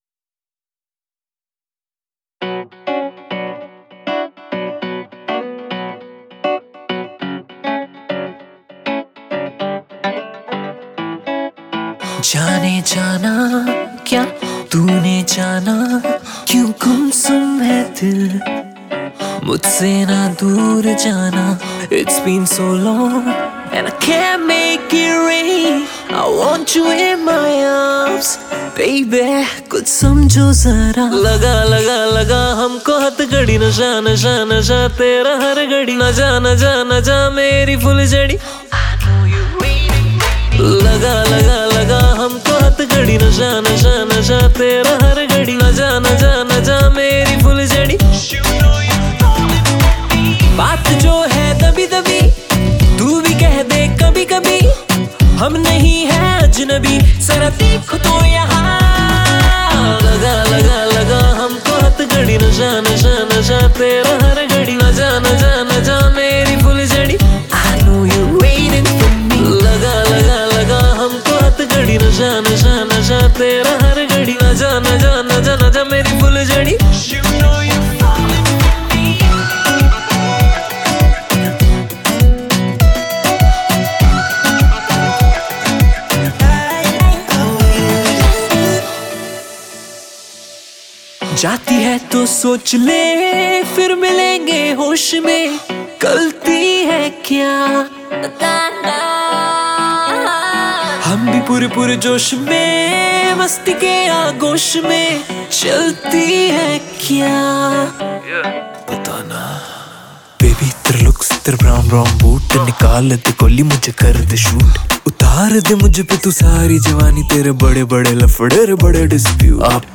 INDIPOP MP3 Songs